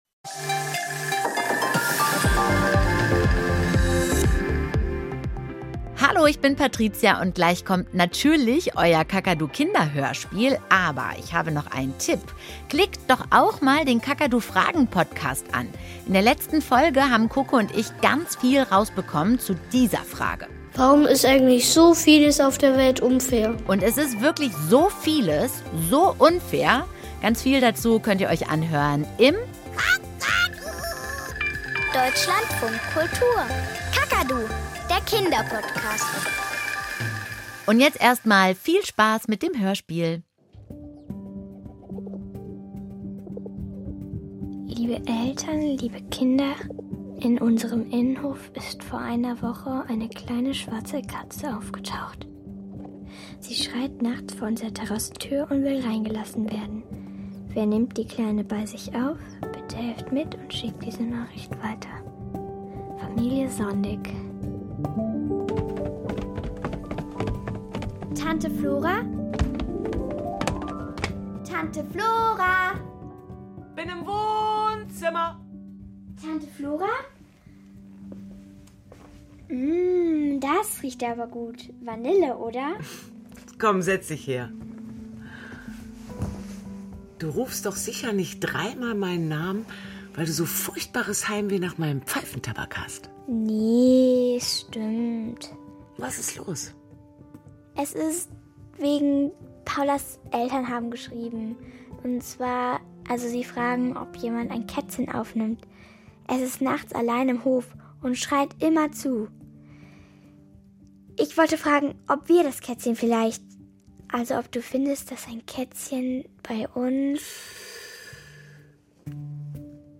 Kinderhörspiel - Mini ist weg